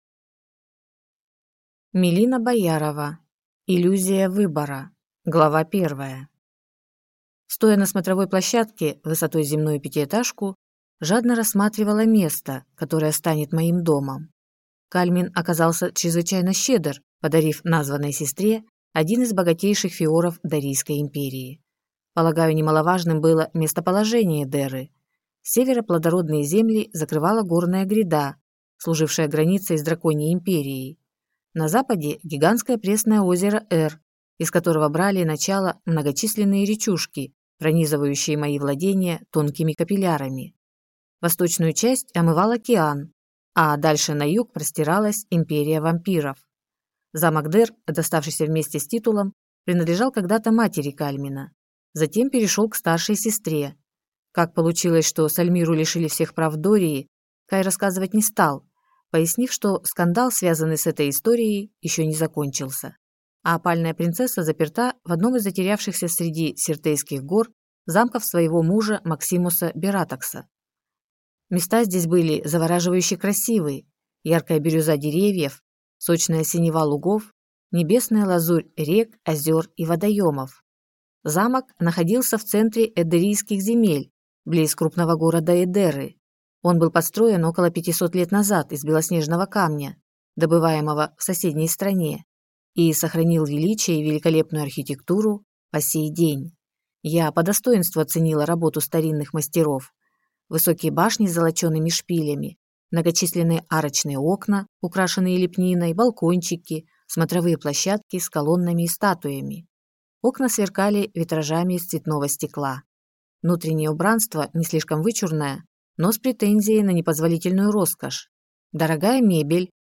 Аудиокнига Иллюзия выбора | Библиотека аудиокниг